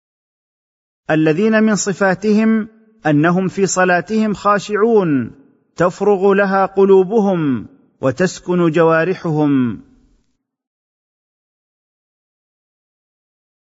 التفسير الميسر [ قراءة صوتية ] [ 023 ] سورة المؤمنون